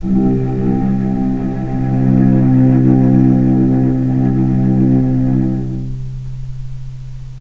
cello
vc-C2-pp.AIF